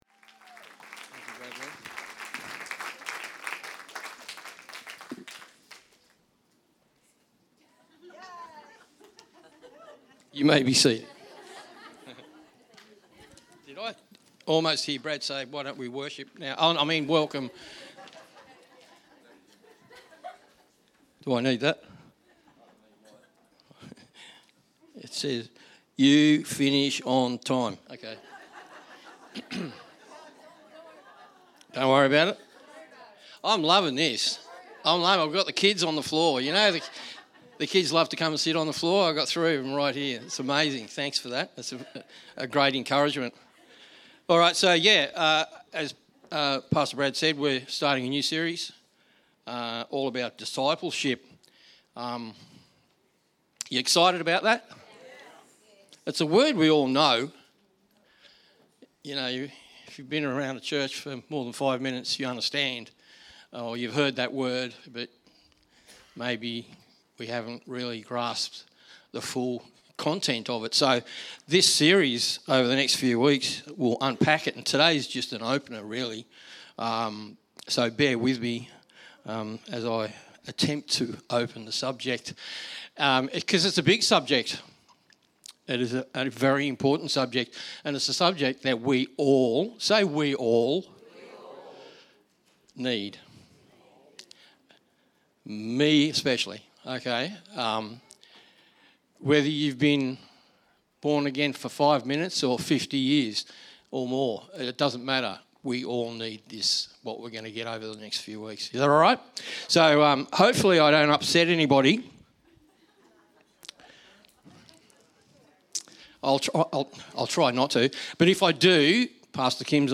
A podcast of our weekly message is recorded at Liberty Family Church, Gosford.